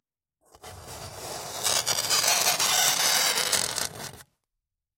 Раздражающий звук царапанья ногтями по доске, столу или стене